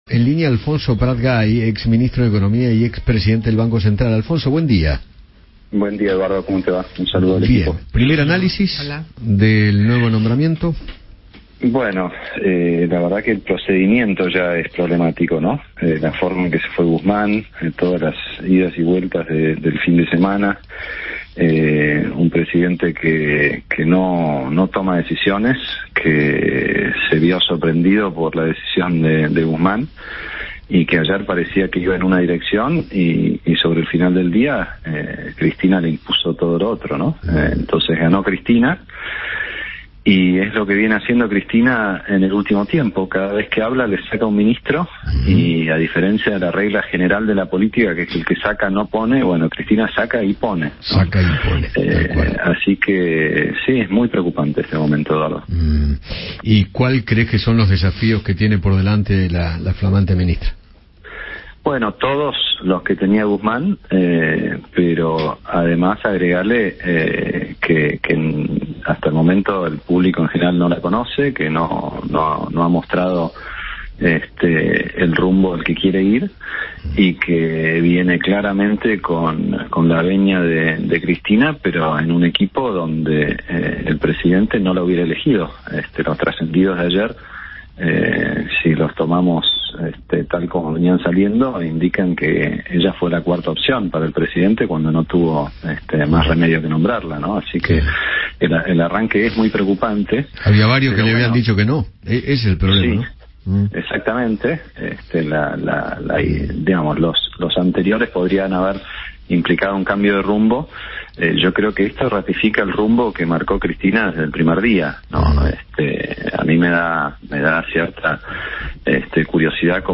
Alfonso Prat Gay, economista y ex presidente del Banco Central de Argentina, charló con Eduardo Feinmann sobre las modificaciones de último momento en la cúpula del Ministerio de Economía.